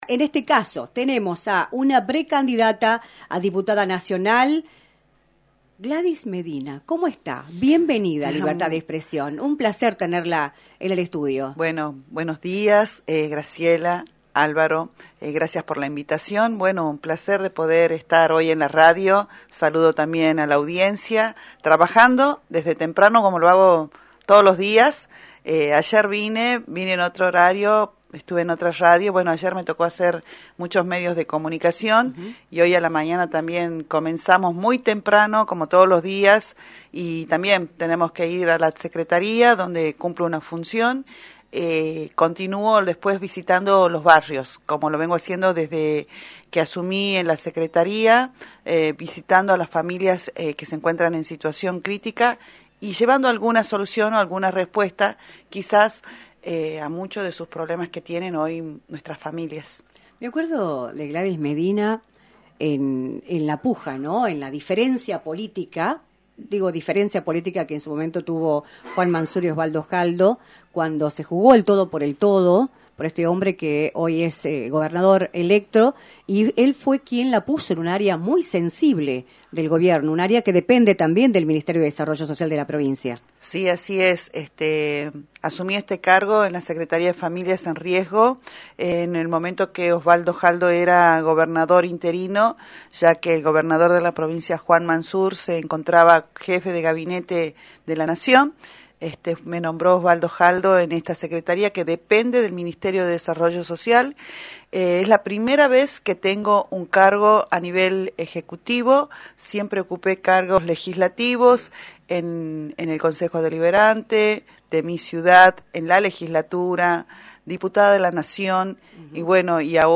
Gladys Medina, Legisladora electa y candidata a Diputada Nacional en segundo término por Unión por la Patria, visitó los estudios de «Libertad de Expresión», por la 106.9, para analizar el escenario político a nivel nacional, luego de que se oficializaron las candidaturas para las PASO, las cuales se llevarán a cabo en agosto.